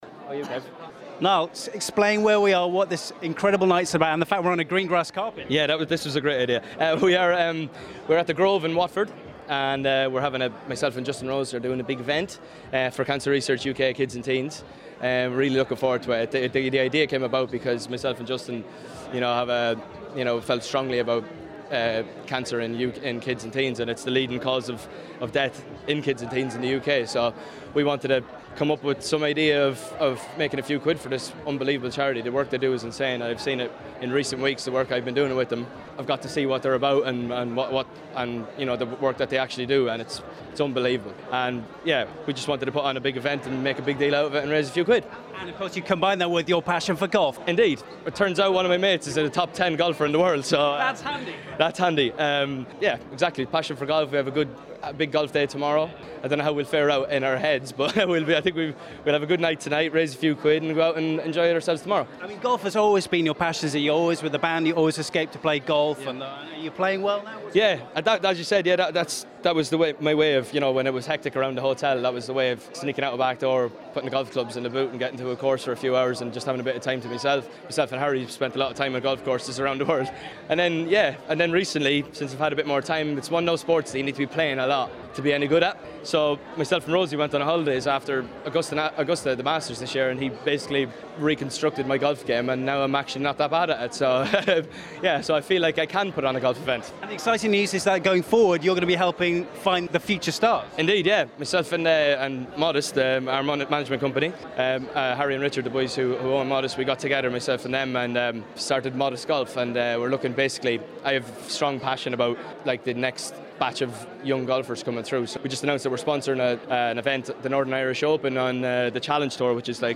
at the Horan and Rose charity gala evening, May 2016.